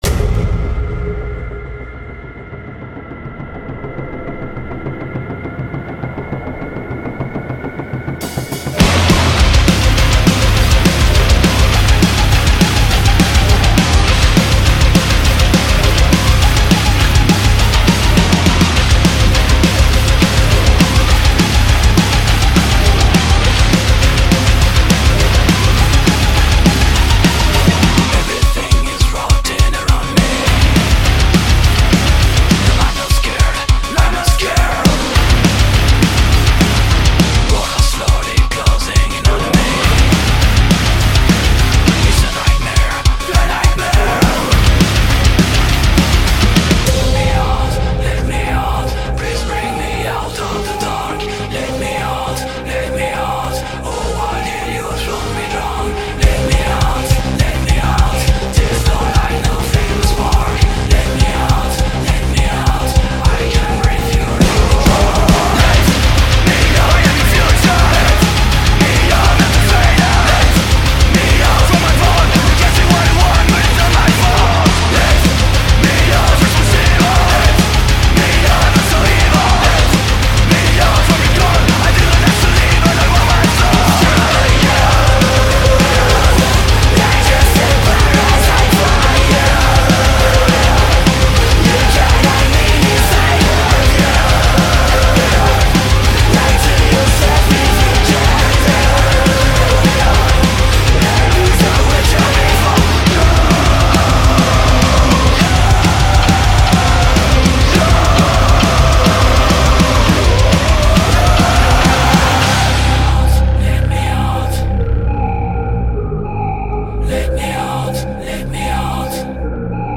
BPM203-206
Audio QualityPerfect (Low Quality)